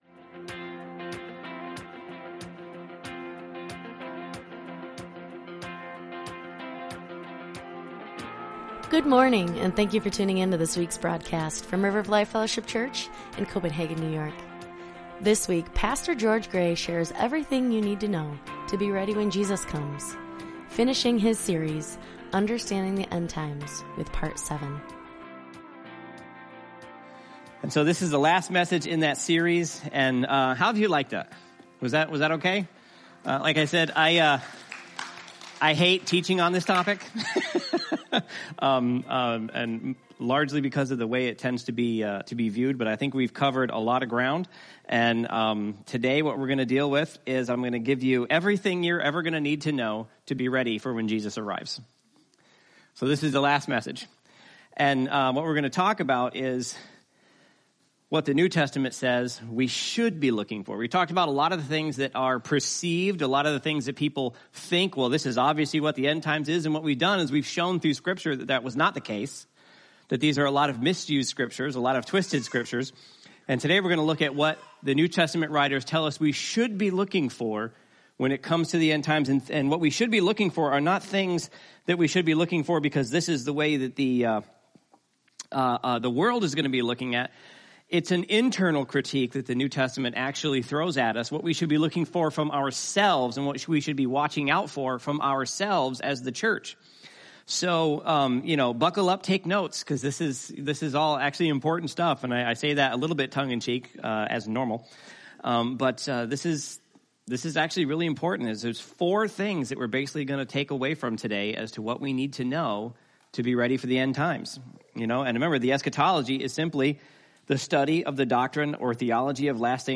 Sermons | River of Life Fellowship Church